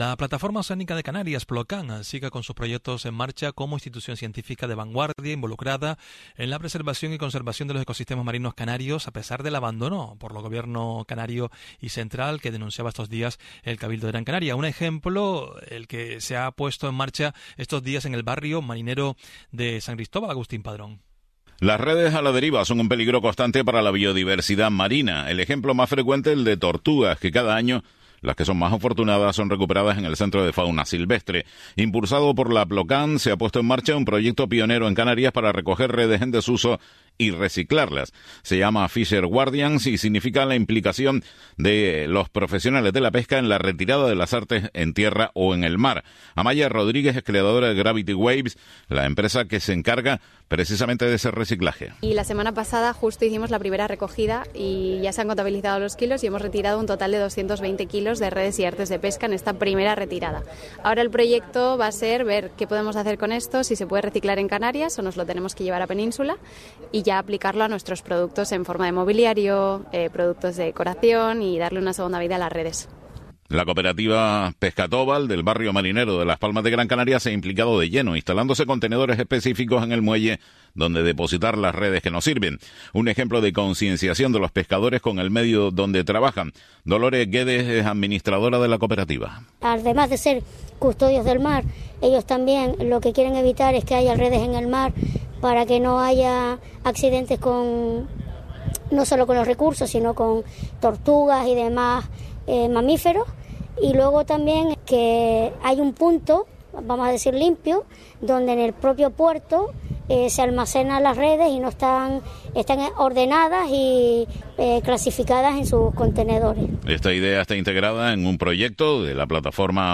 Radio interviews: